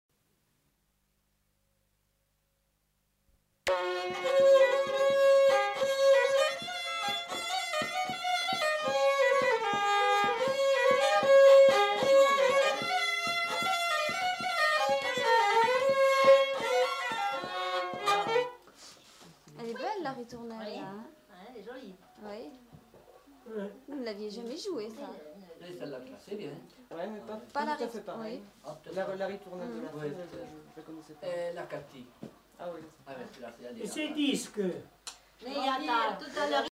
Aire culturelle : Limousin
Lieu : Lacombe (lieu-dit)
Genre : morceau instrumental
Instrument de musique : violon
Danse : valse
Notes consultables : Le second violon est joué par un des enquêteurs. Coupure en début de séquence.